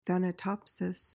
PRONUNCIATION:
(than-uh-TOP-sis)